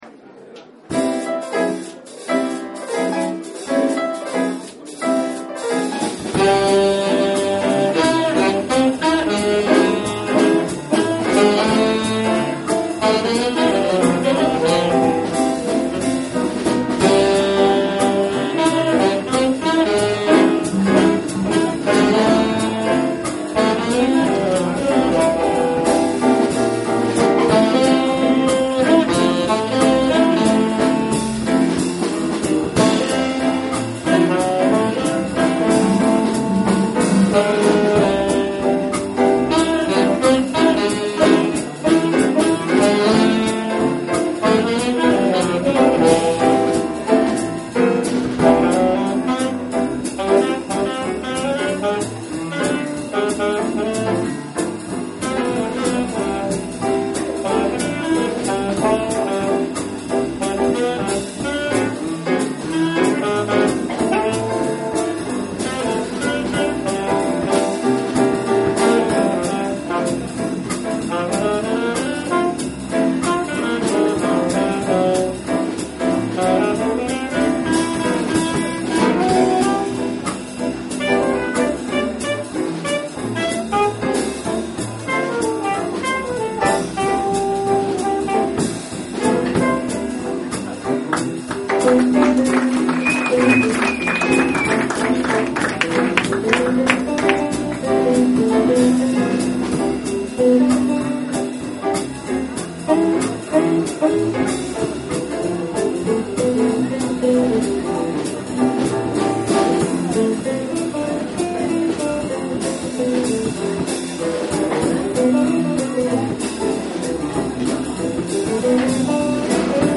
Concert des Ensembles Jazz du 2 juillet 2011 au Planet Mundo K'fé